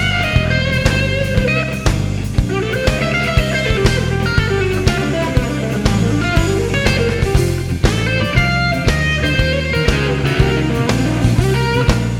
Diminished Scales and The Cool Riff